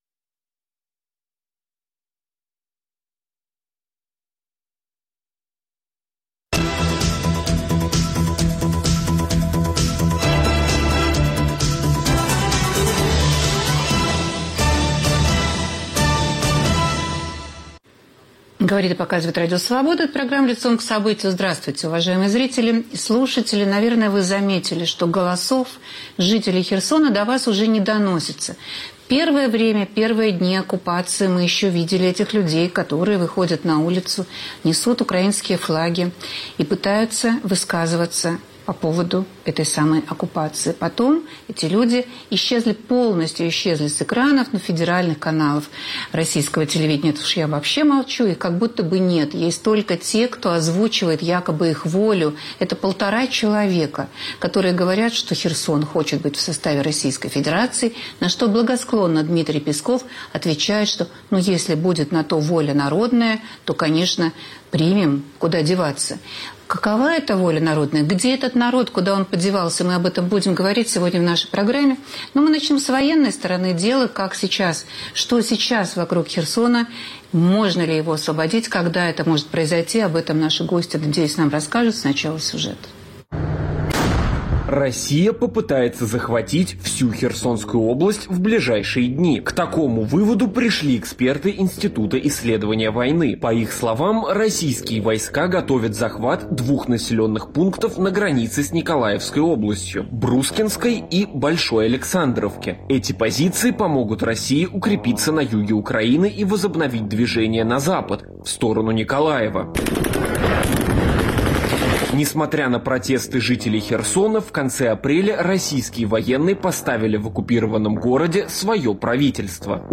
В программе "Лицом к событию" участвуют: военный эксперт